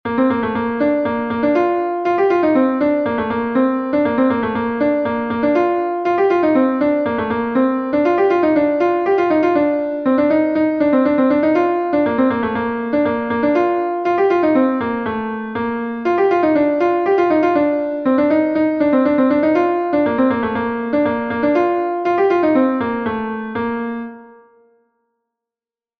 Gavotenn Leuelan I is a Gavotte from Brittany